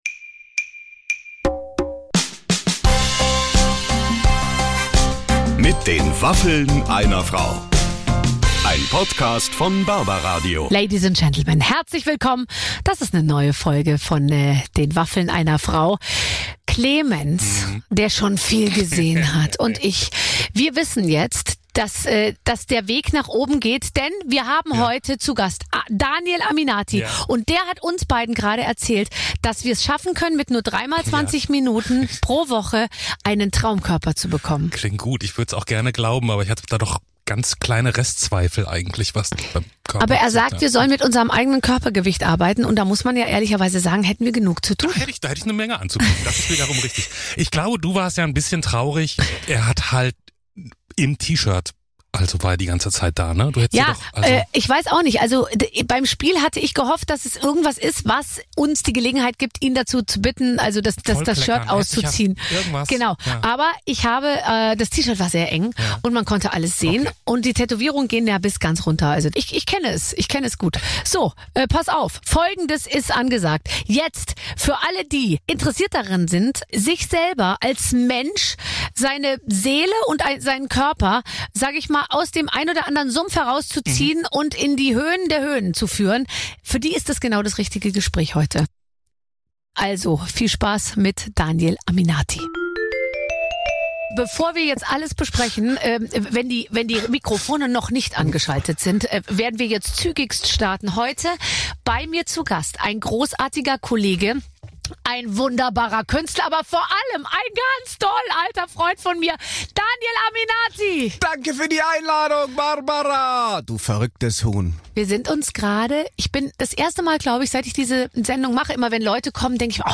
Er erzählt von seinem Erfolg mit der Bobyand "Bed & Breakfast" und wird auch sehr ehrlich, wenns um die Zeit nach dem Ruhm geht. Aber eins ist sicher, so oft und laut hat Daniel Aminati noch nie gelacht!